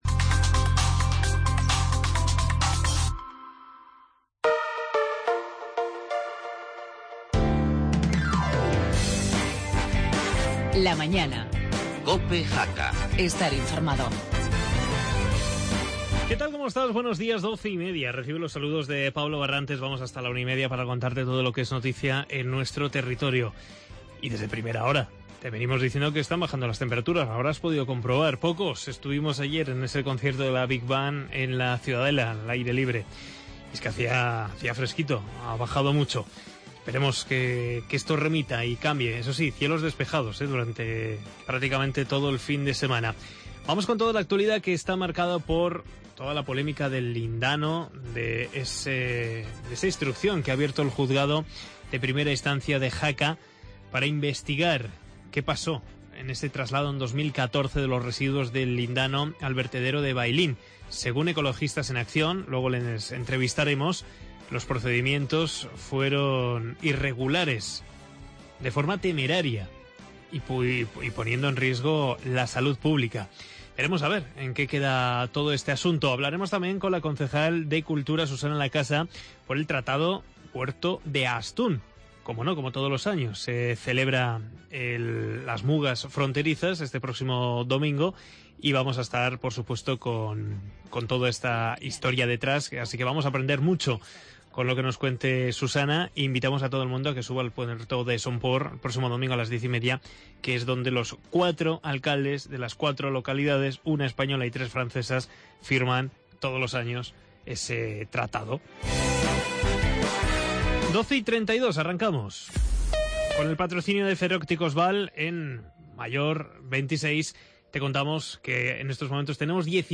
Actualudad de la jornada, entrevistas a Susana Lacasa, concecal de cultura, para hablar de las mugas fronterizas